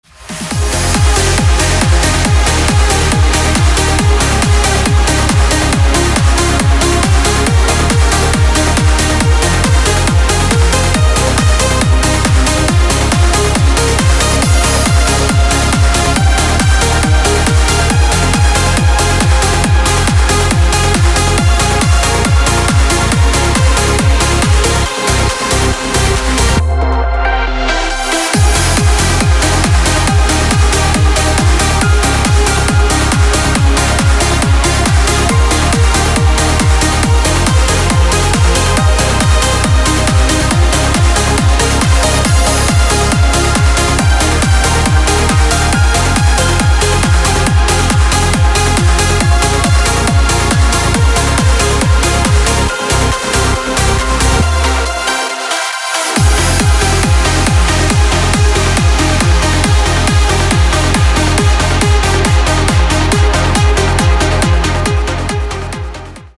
• Качество: 224, Stereo
громкие
dance
Electronic
EDM
электронная музыка
без слов
club
Trance